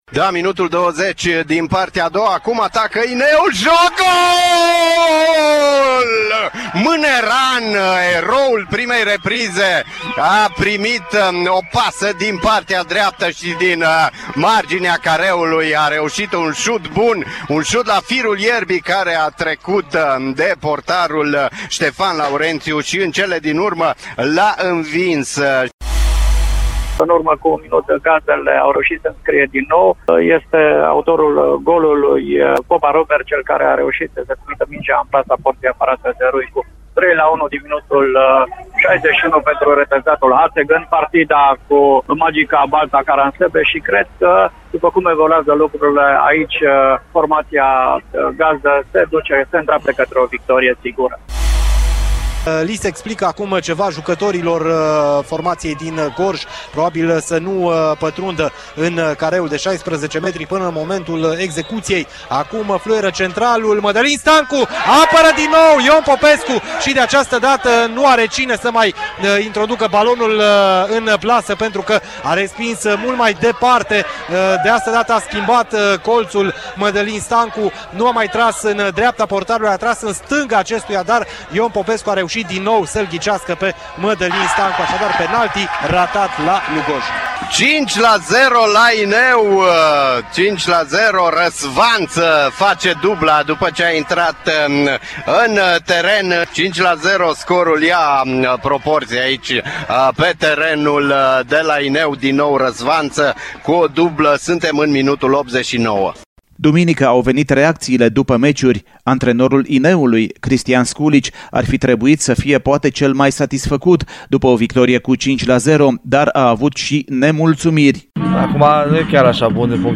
Meciurile din tur și declarațiile celor implicați le-ați putut urmări, de asemenea, la Radio Timișoara, iar rezumatul lor este în fișierul AUDIO de mai jos: